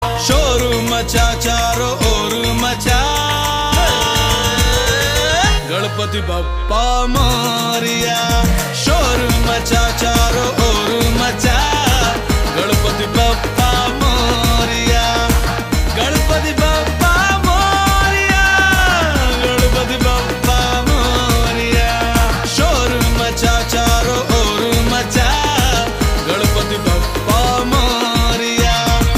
Hindi Songs
A Melodious Fusion